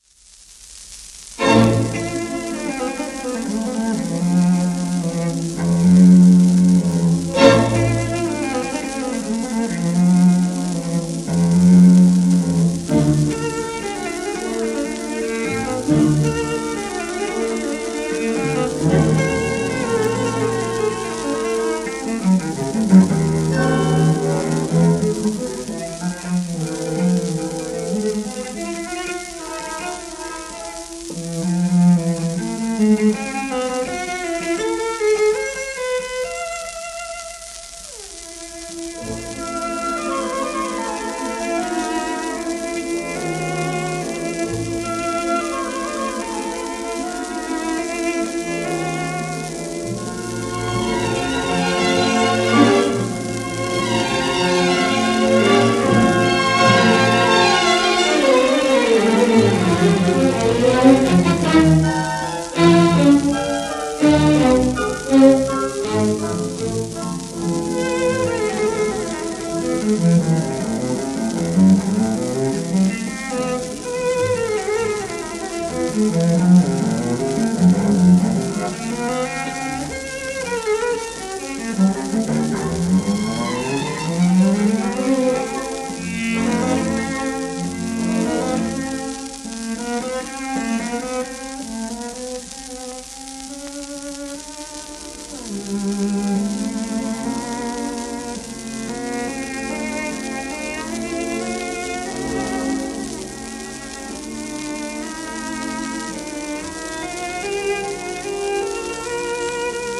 1949年頃の録音